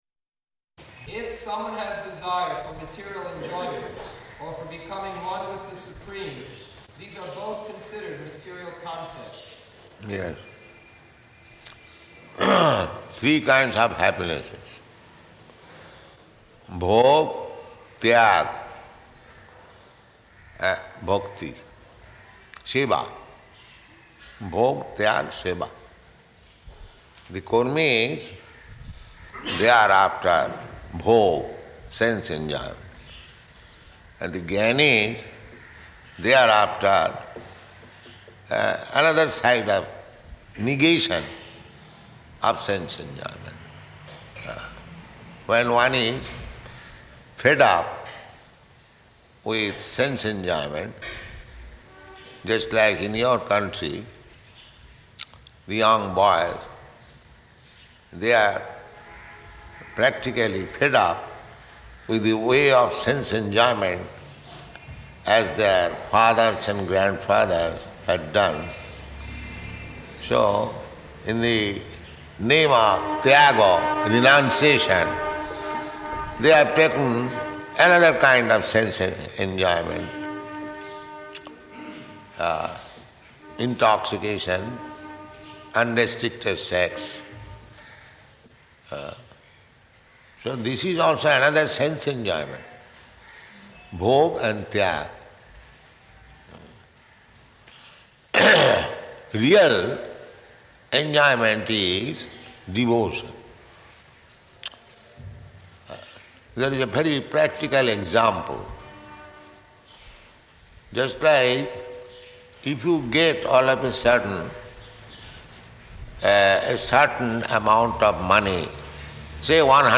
Location: Calcutta